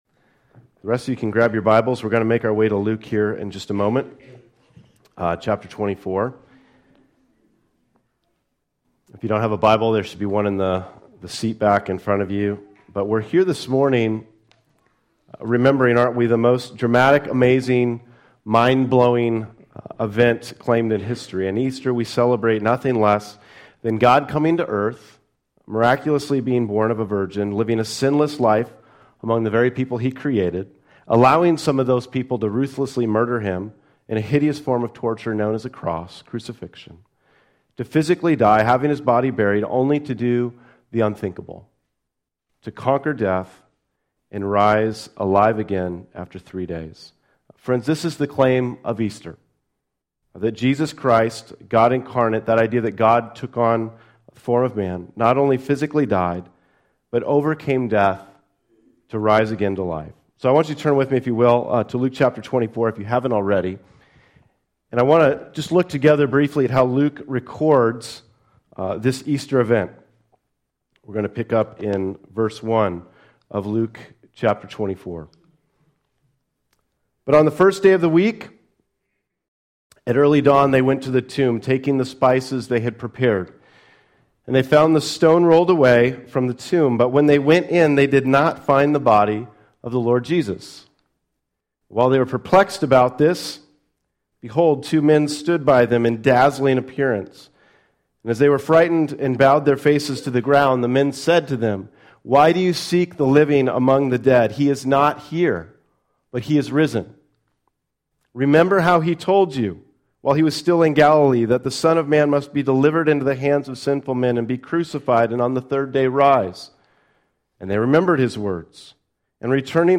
Today’s Easter sermon focuses on what we might consider, “The Problem of Easter”.